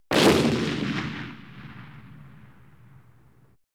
На этой странице собраны реалистичные звуки выстрелов из пушек разной мощности.
Гул одиночного залпа пушки